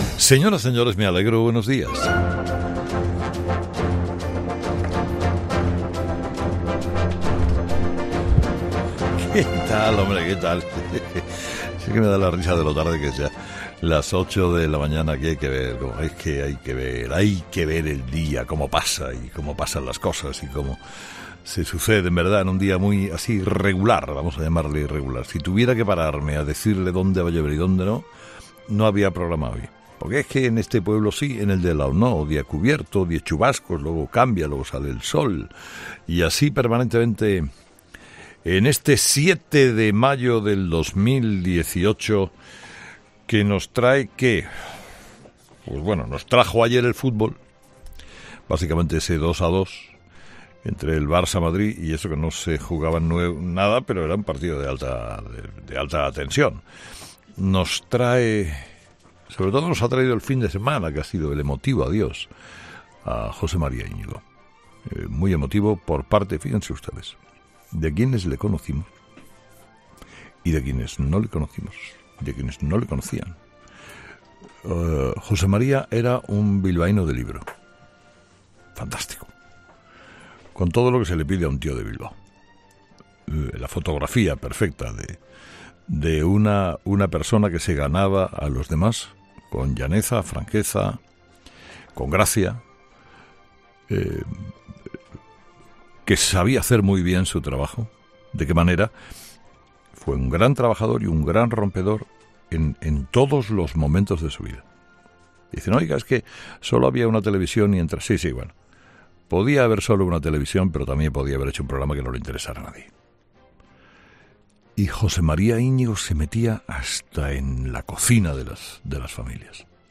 Monólogo de las 8 de Herrera 'Herrera en COPE'